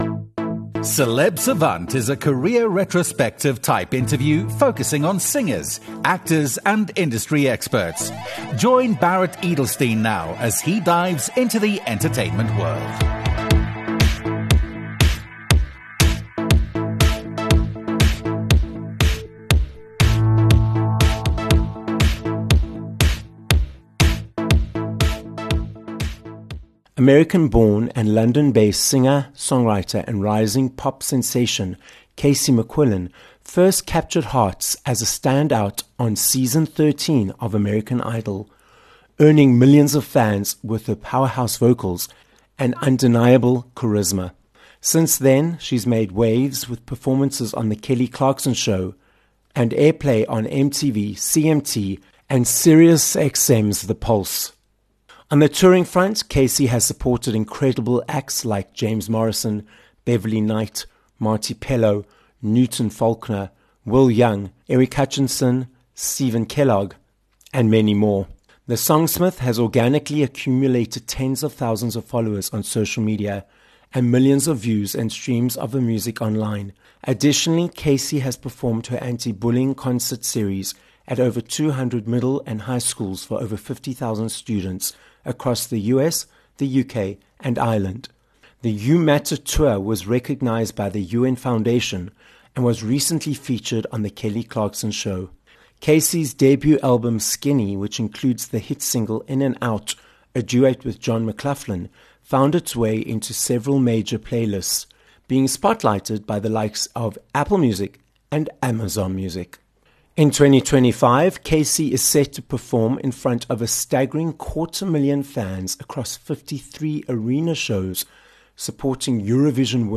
joins us on this episode of Celeb Savant… from a tour bus in Budapest! In 2025, she will be performing to a quarter of a million people, supporting Loreen and Anastacia on their tours.